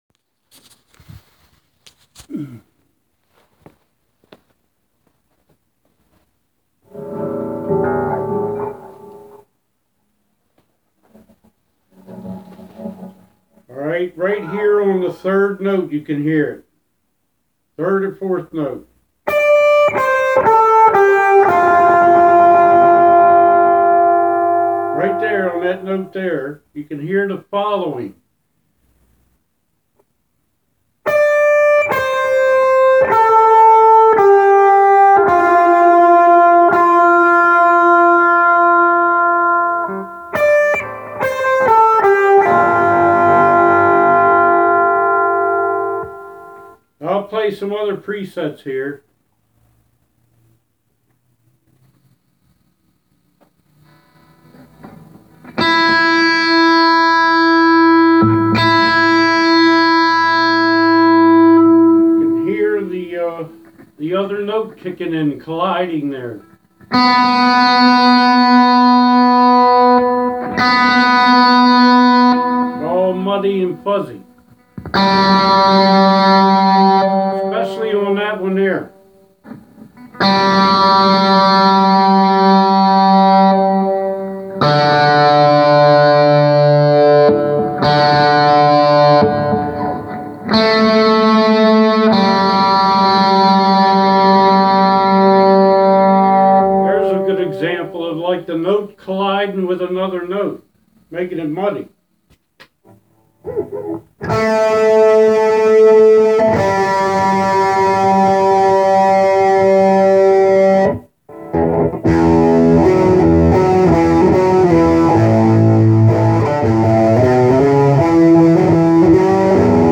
I use a guitar-zoom g5n multi effects pedal-into computer&daw or fender hot rod deville 4/10 or from the computer to two 5" powered studio monitors..the trouble is, no matter which way I route it or which outlets I use or which cables or strings or impedance rigging I can come up with, the...